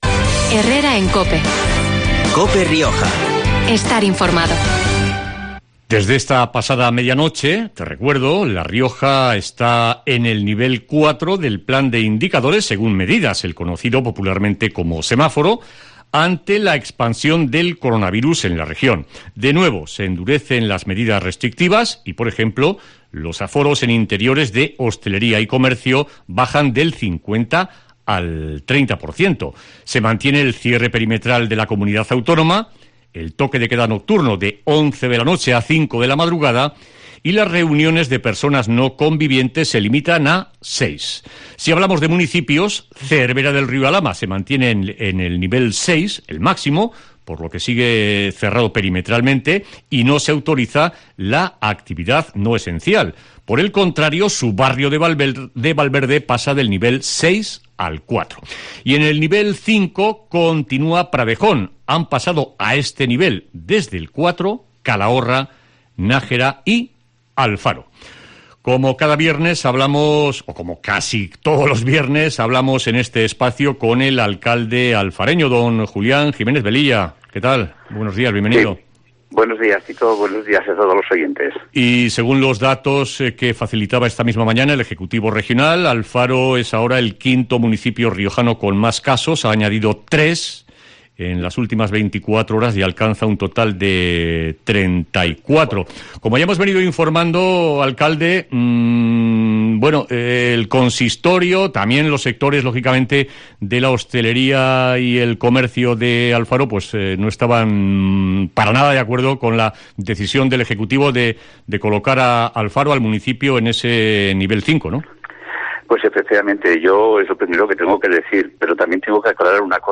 Entrevista en COPE Rioja a Julián Jiménez Velilla, alcalde de Alfaro